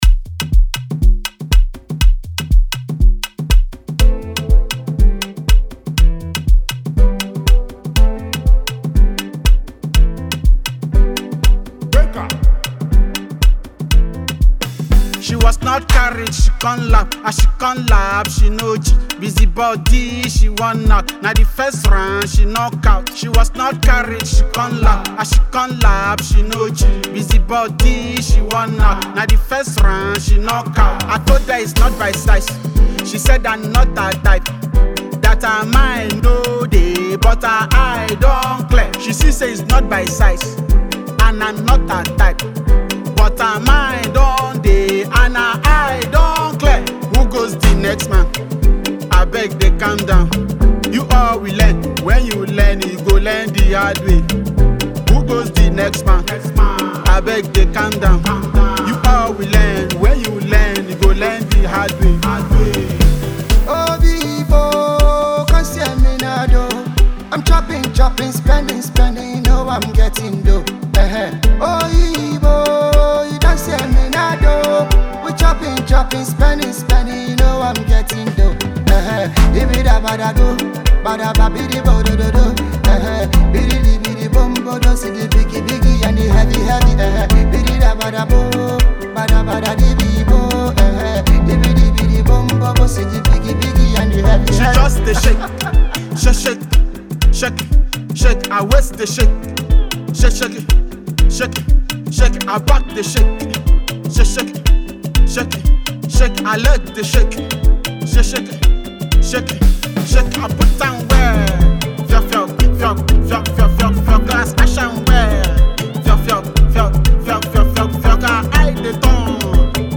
Afrobeats and afro fusion